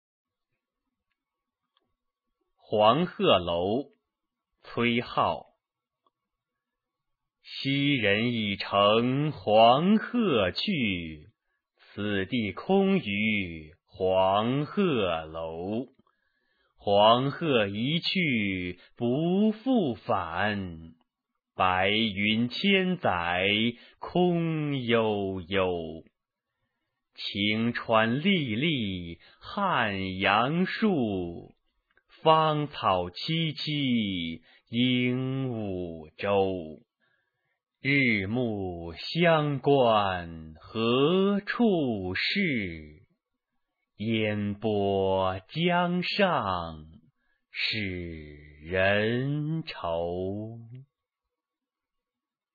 崔颢《黄鹤楼》原文与译文、赏析、朗读　/ 崔颢